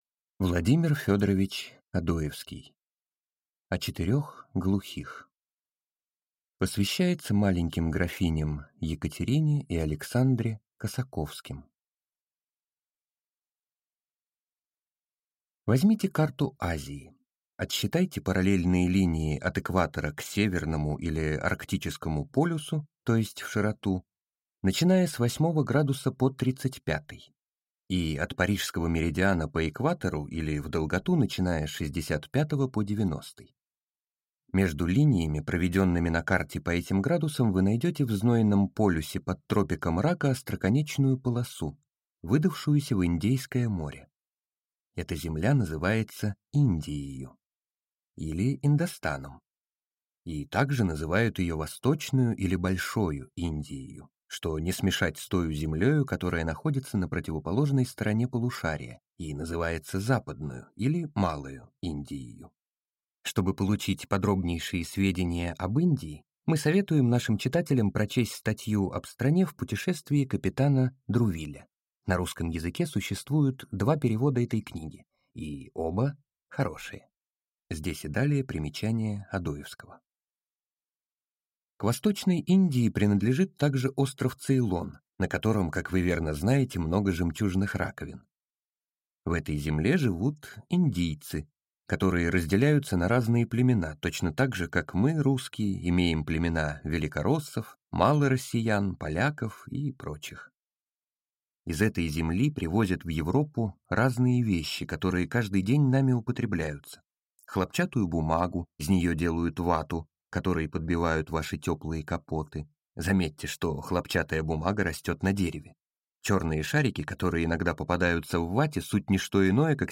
Аудиокнига О четырех глухих | Библиотека аудиокниг